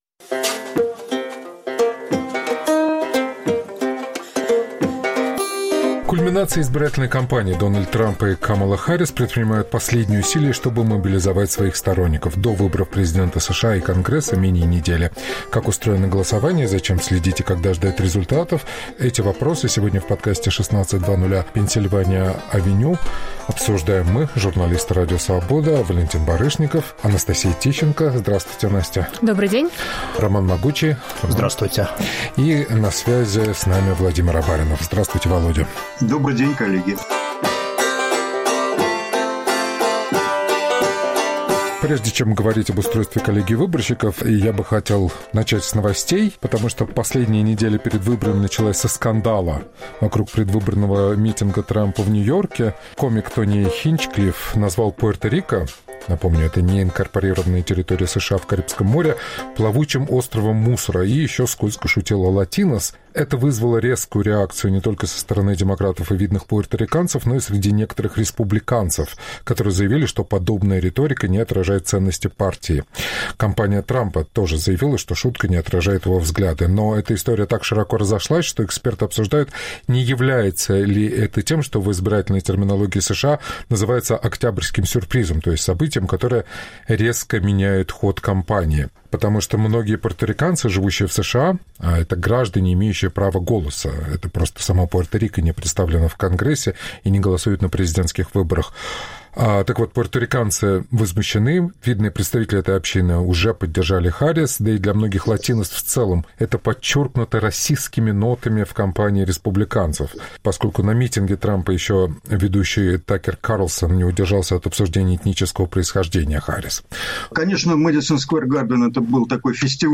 обсуждают журналисты